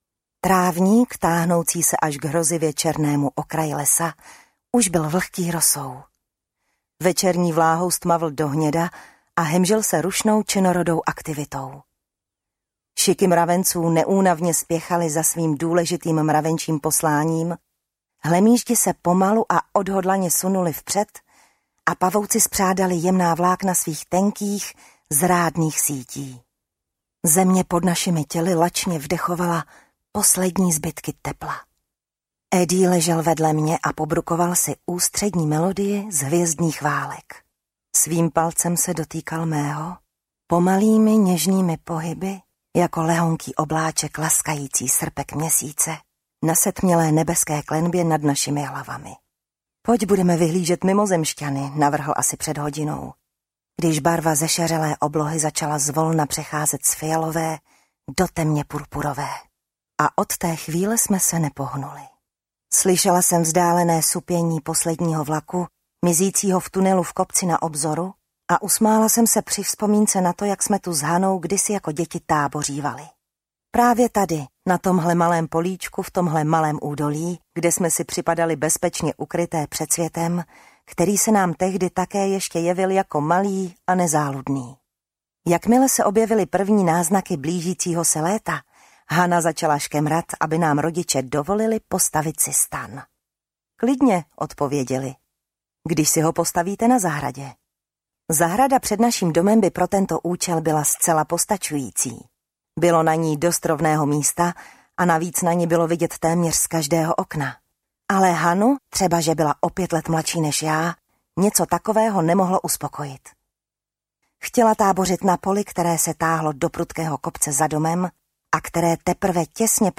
Muž, který už nezavolal audiokniha
Ukázka z knihy